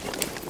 tac_gear_29.ogg